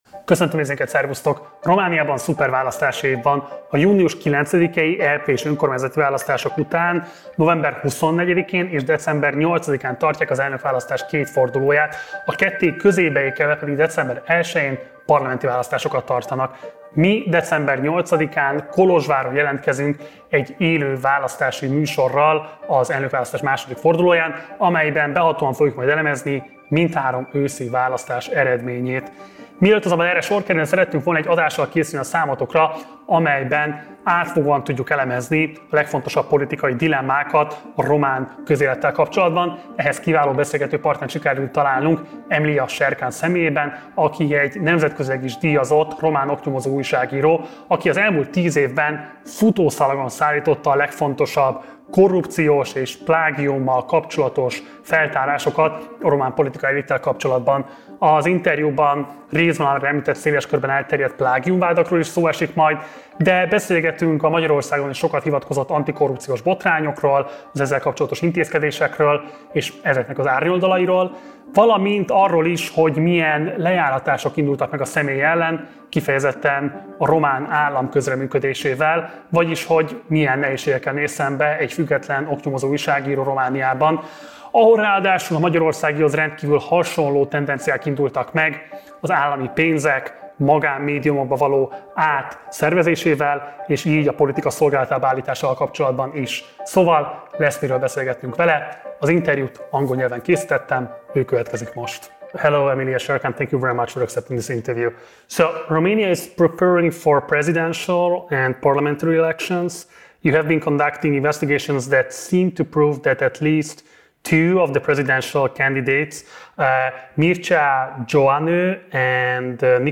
1 Románia már nem korrupcióellenes minta | Interjú